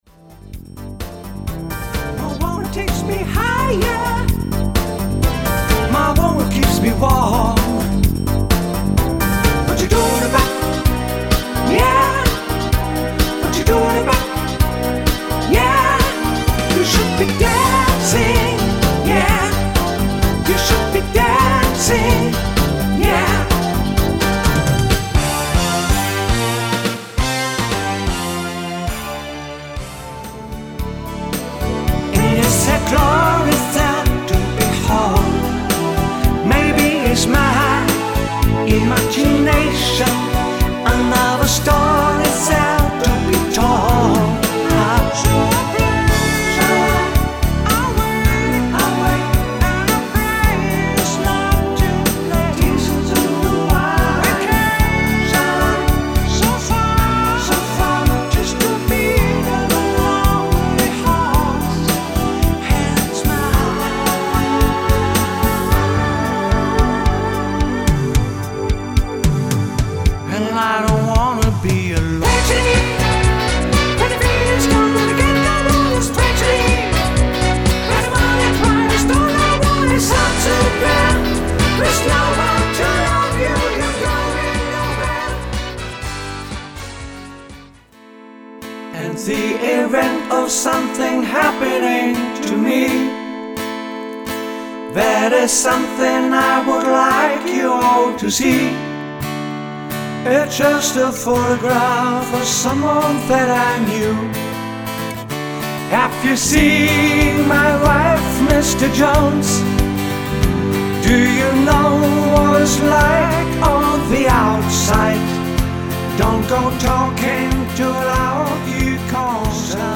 SHOW-ACT und Musik zum tanzen
Gesang und Gitarre - mit oder ohne Halbplaybacks.
• Coverband